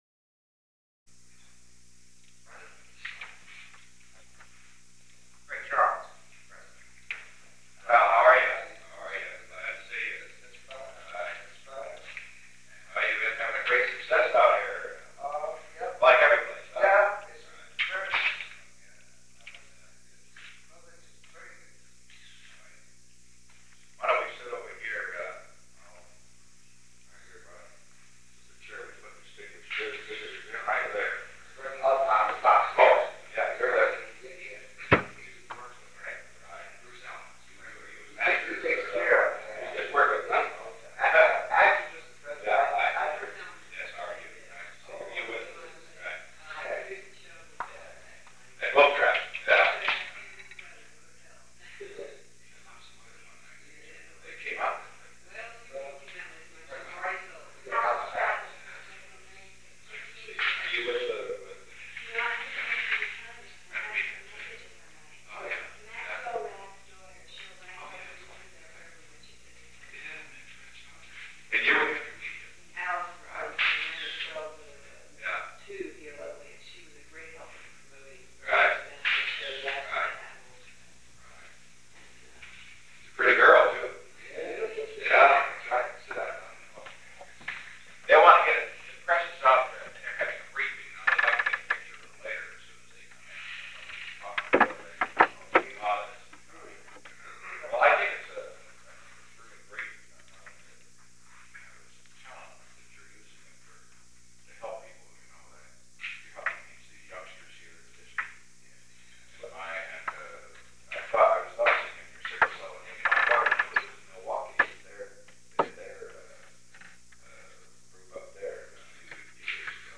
The President of the United States began a conversation with the blind king of soul music.